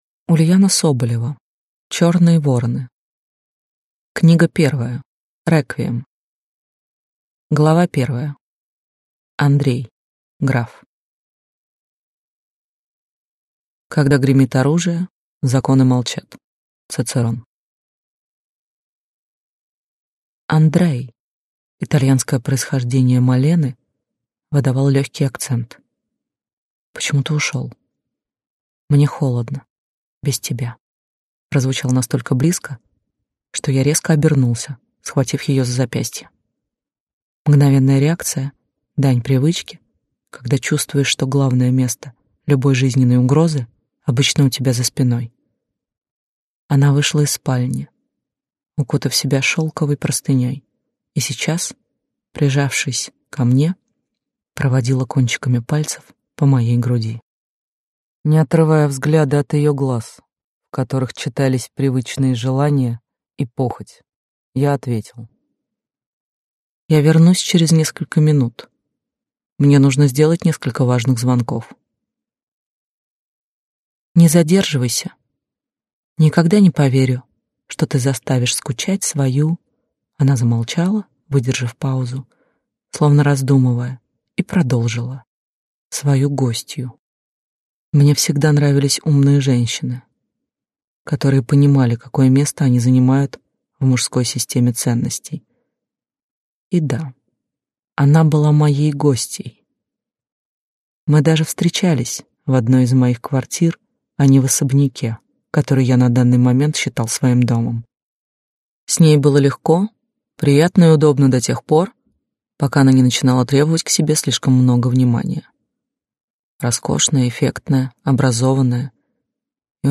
Аудиокнига Черные Вороны 1. Реквием | Библиотека аудиокниг